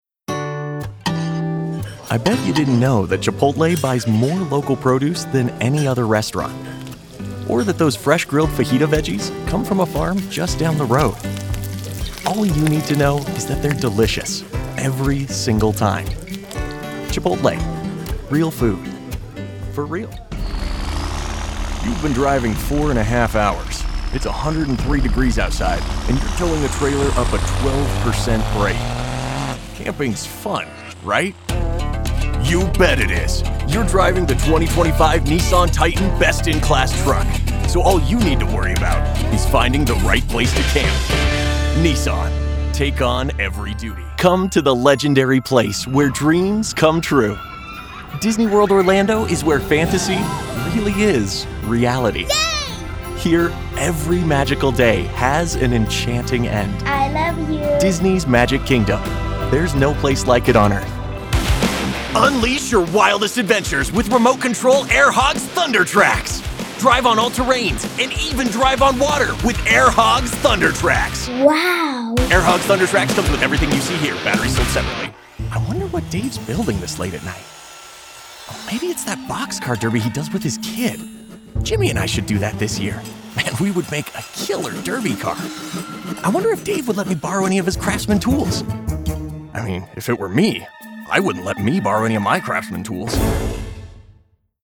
Commercial Demo
General American, General Canadian, South West - Texas
Young Adult
Middle Aged